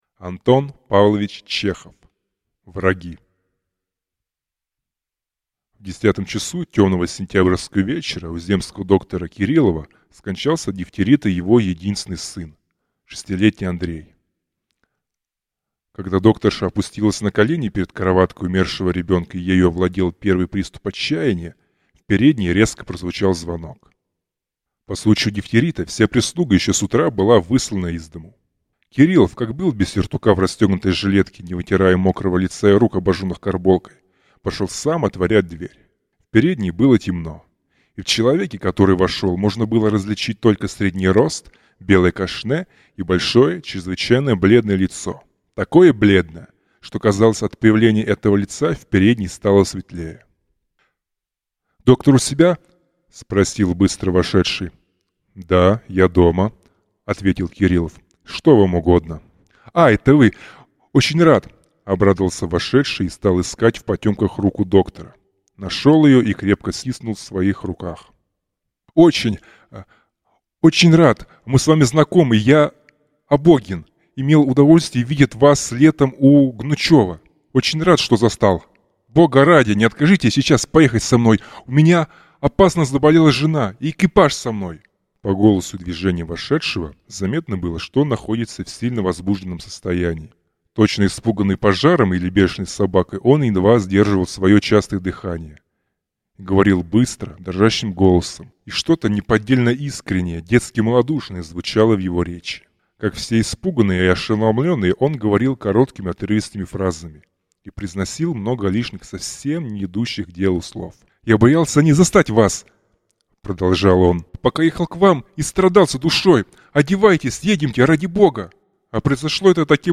Аудиокнига Враги | Библиотека аудиокниг